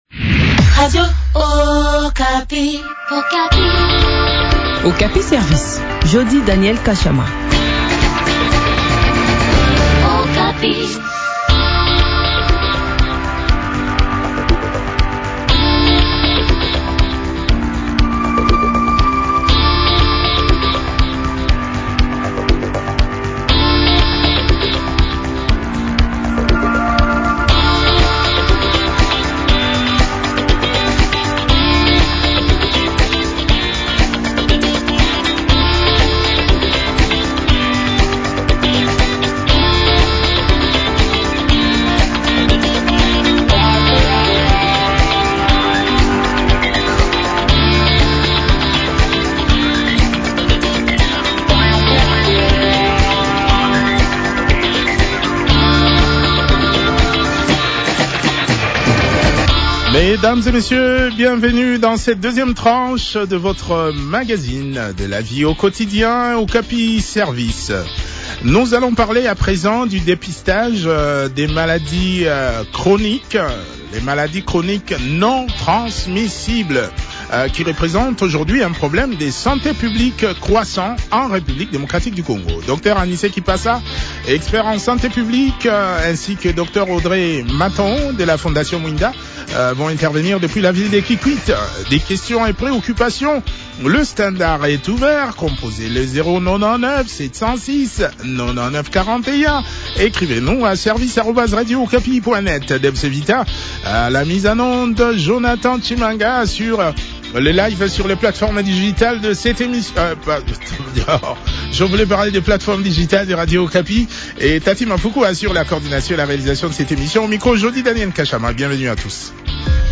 expert en santé publique.